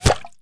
pgs/Assets/Audio/Guns_Weapons/Bullets/bullet_impact_water_07.wav
bullet_impact_water_07.wav